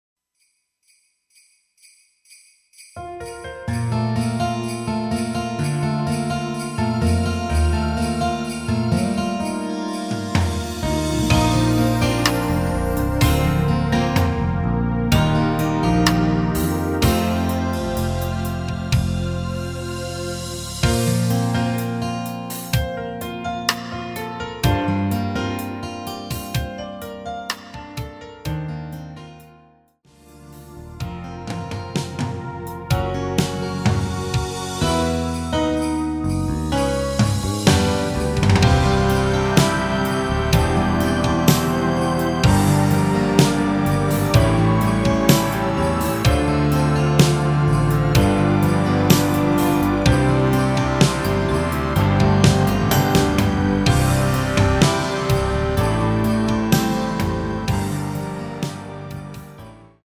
엔딩이 페이드아웃이라 엔딩을 만들어 놓았습니다.
Bb
앞부분30초, 뒷부분30초씩 편집해서 올려 드리고 있습니다.